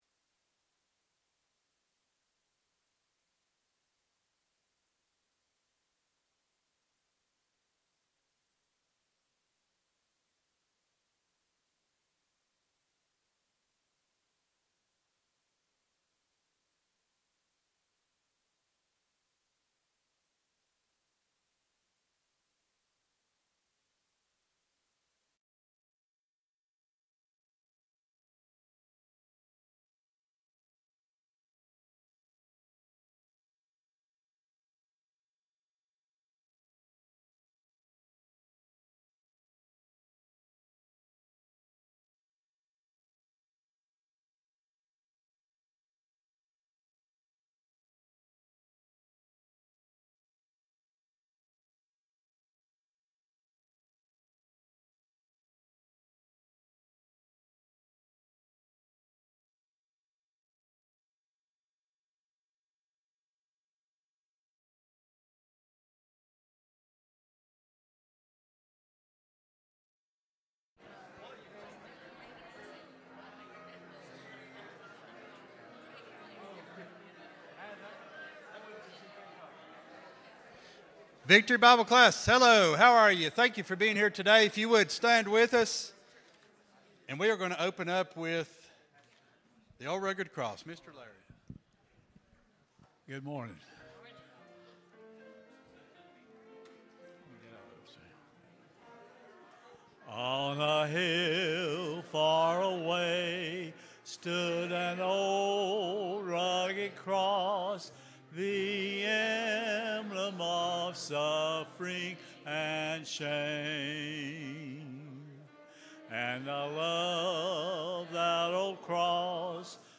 Sunday School Lesson